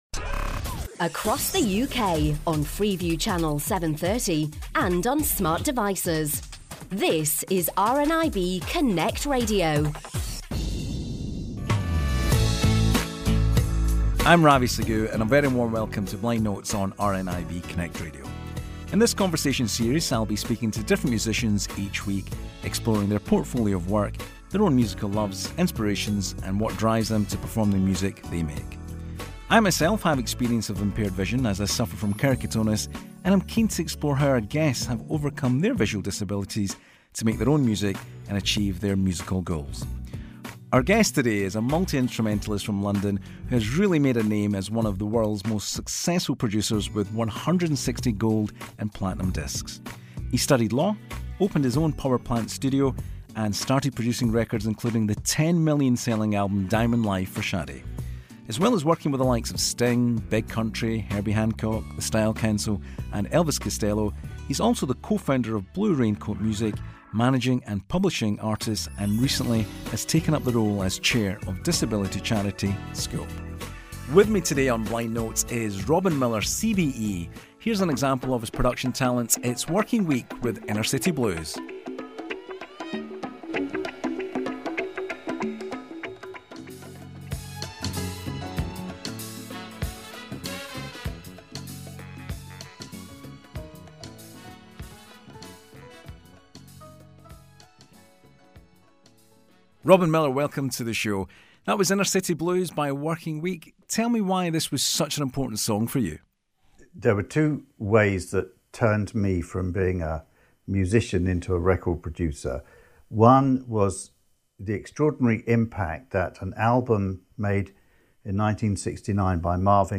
Blind Notes a seven-part conversation series of radio programmes featuring one-to-one interviews with musicians from around the world, all of whom are completely blind.